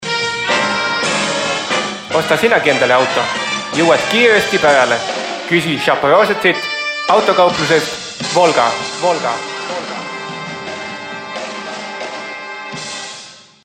Estonian speaker, voice over, audio book narrator
Sprechprobe: Sonstiges (Muttersprache):